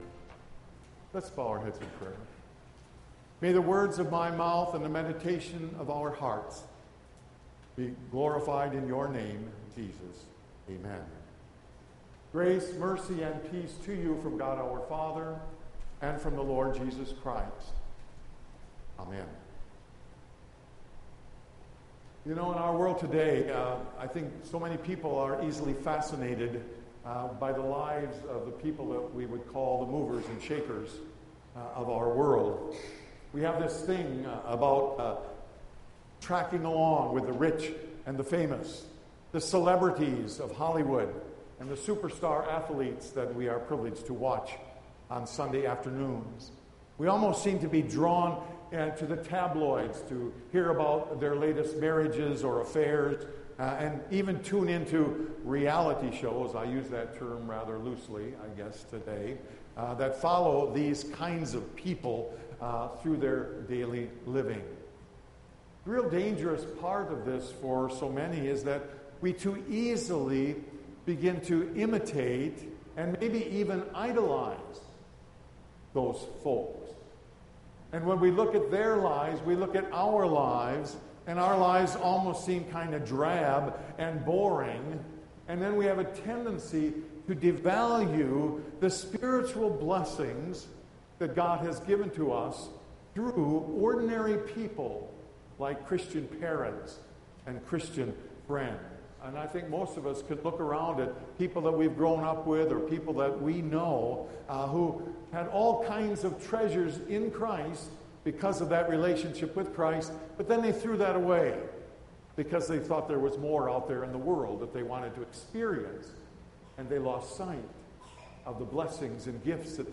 June 21, 2020 Sermon